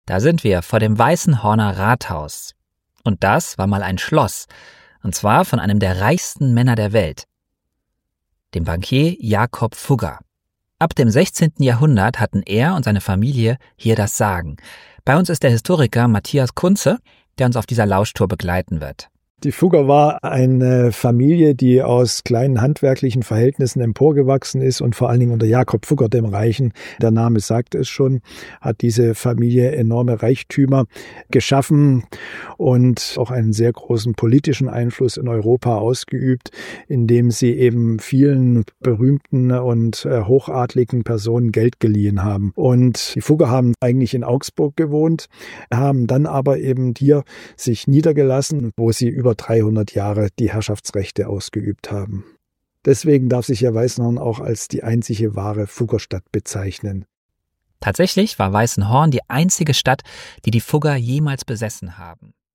Auf Lauschtour in Weißenhorn: Gemeinsam mit zwei Weißenhorn-Kennern erkunden wir die Stadt, die mal einem der reichsten Männer der Welt gehörte: dem legendären Bankier Jakob Fugger.